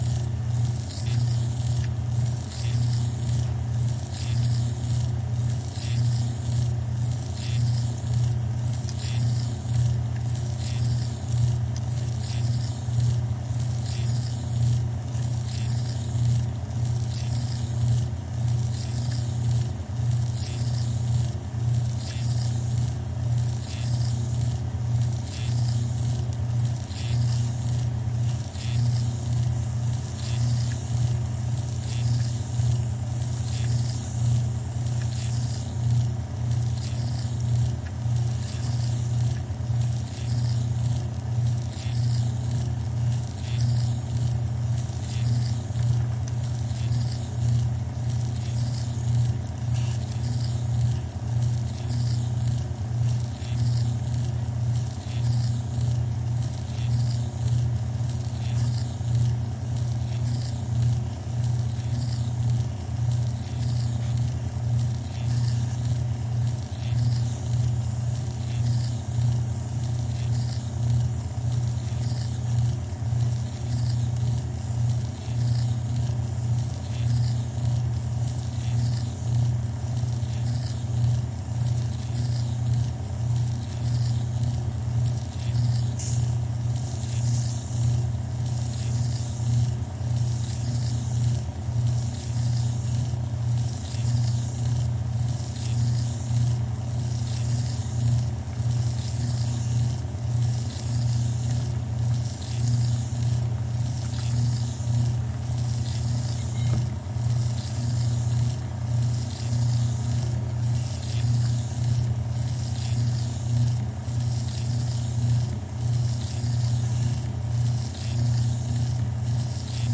盒式磁带 " 卷对卷磁带机单卷转动
Tag: 卷筒 卷筒 磁带 机器 转弯 无论